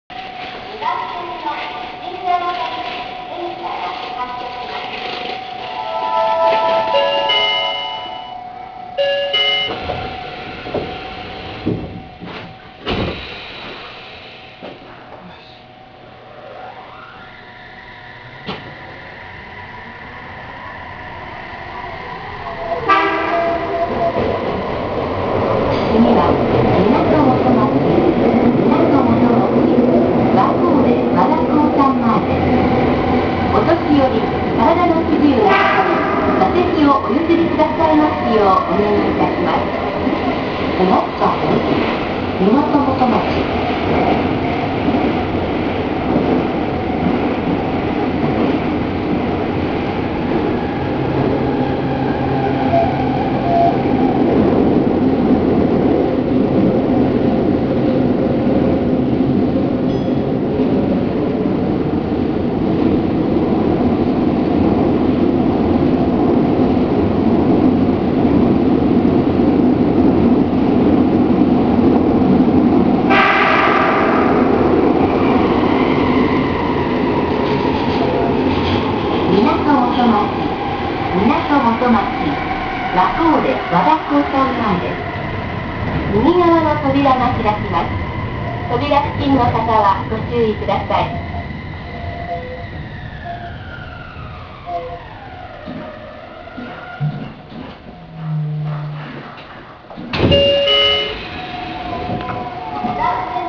〜車両の音〜
・5000形走行音
【海岸線】ハーバーランド→みなと元町（1分43秒：563KB）
一気に舞い上がる感じの起動音が特徴的。リニアの地下鉄なので、一度音が転調した際に低い音が鳴り響きます。